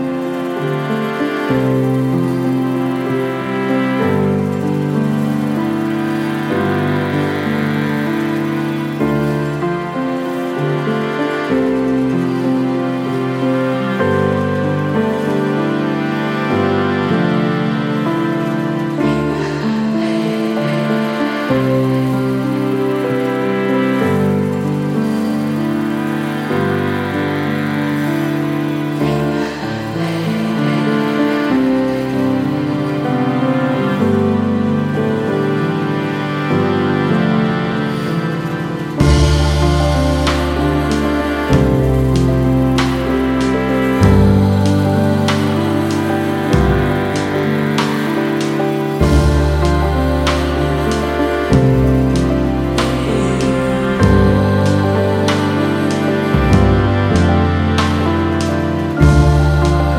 ジャンル(スタイル) ELECTRONICA / DANCE / NU JAZZ / FUNK / SOUL